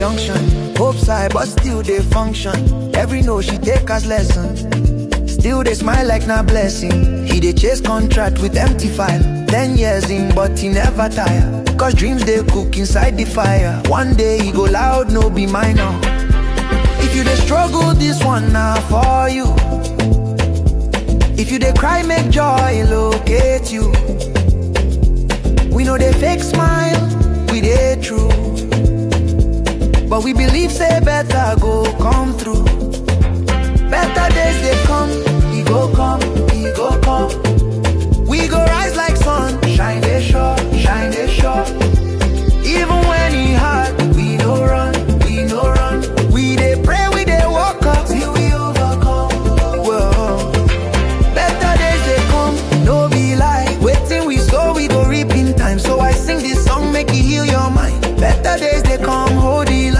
Afrobeats. Purely African music.